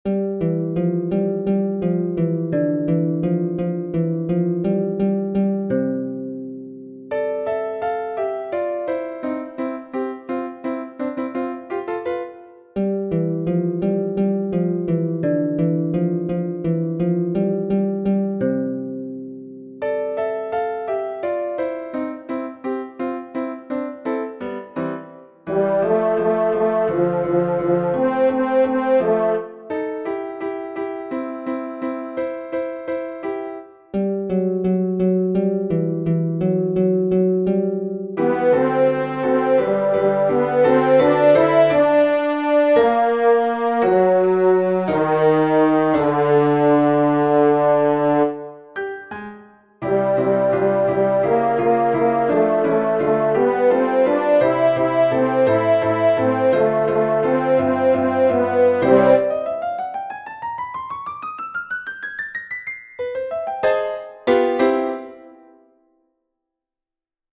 The baritone soloist sounds like a harp. The featured voice is a horn.
TENOR